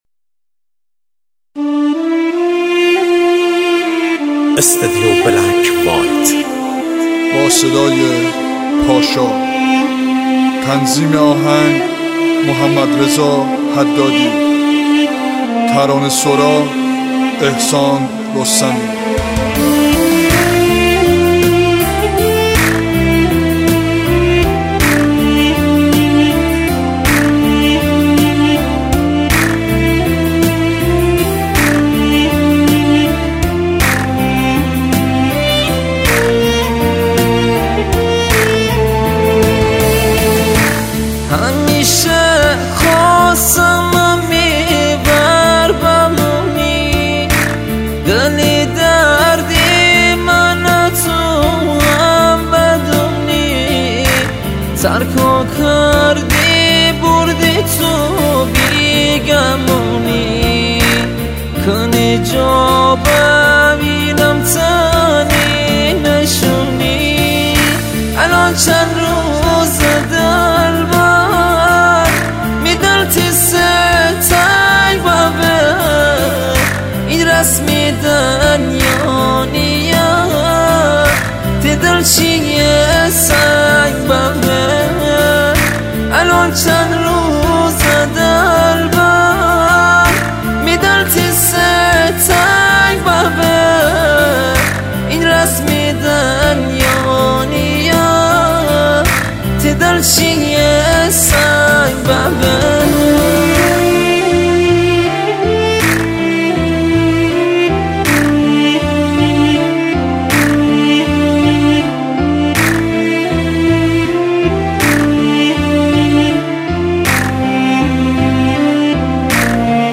دانلود آهنگ مازندرانی جدید و زیبا
آهنگ شاد